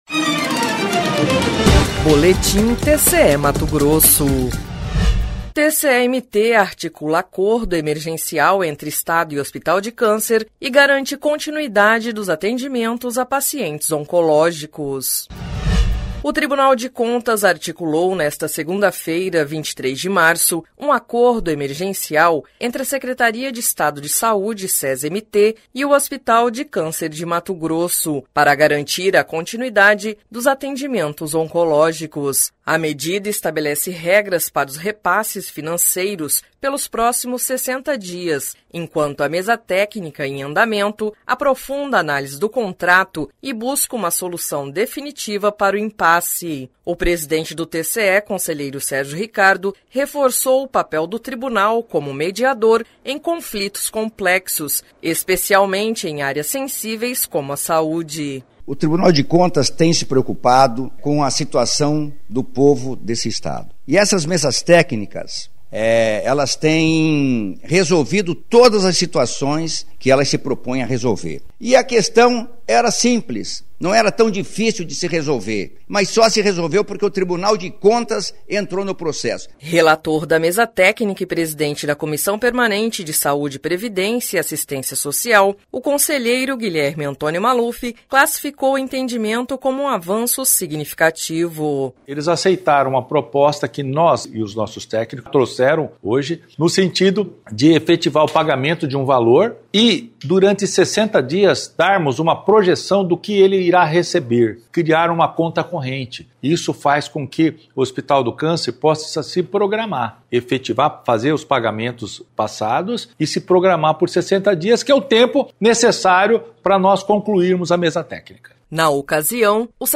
Sonora: Sérgio Ricardo – conselheiro-presidente do TCE-MT
Sonora: Gilberto Figueiredo - secretário de Estado de Saúde
Sonora: William Brito Júnior - procurador-geral do MPC-MT